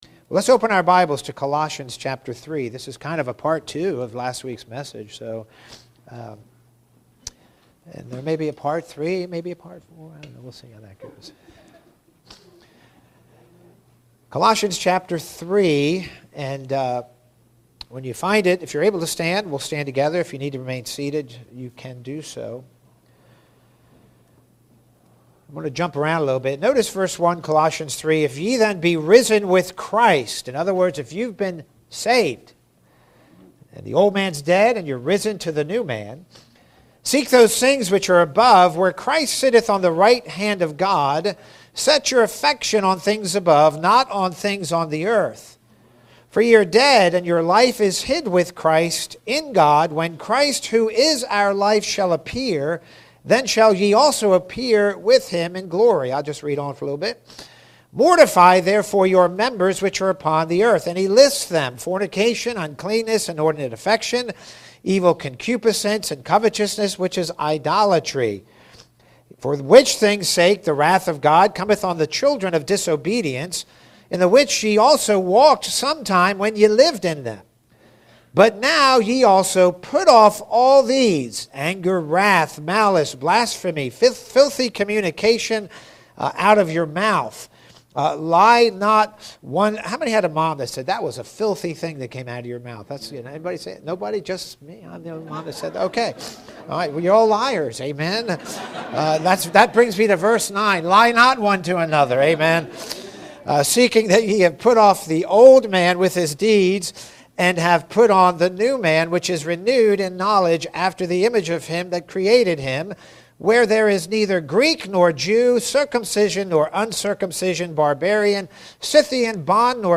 Are You Putting Me On - Part 2 | SermonAudio Broadcaster is Live View the Live Stream Share this sermon Disabled by adblocker Copy URL Copied!